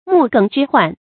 注音：ㄇㄨˋ ㄍㄥˇ ㄓㄧ ㄏㄨㄢˋ
木梗之患的讀法